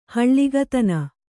♪ haḷḷigatana